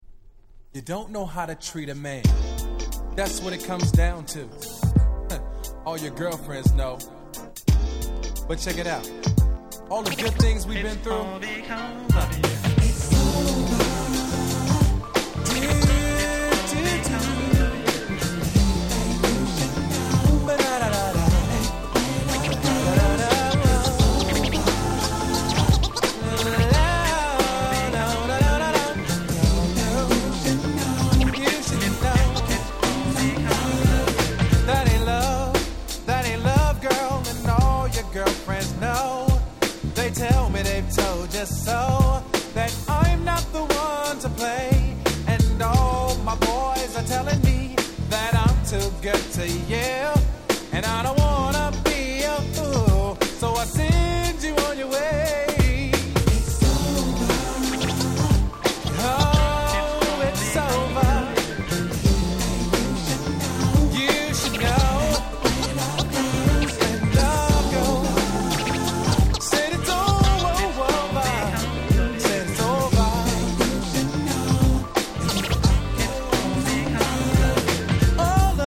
95' Super Hit R&B LP !!